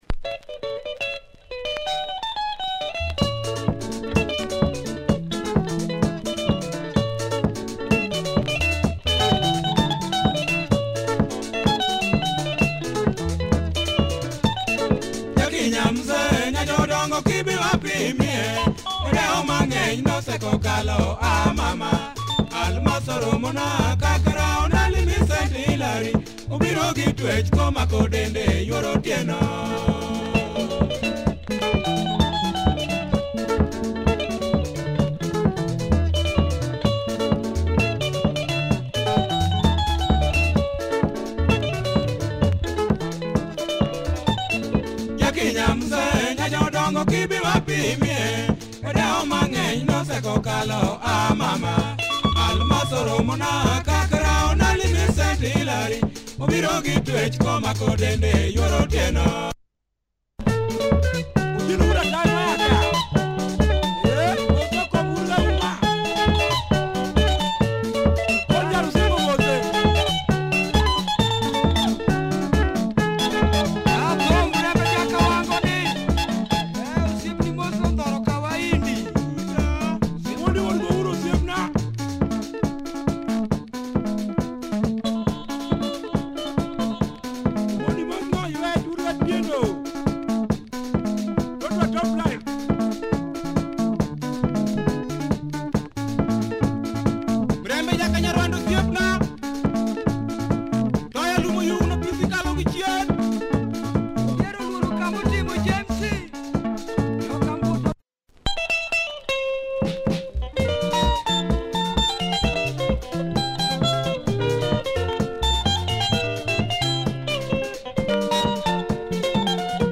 Nice luo benga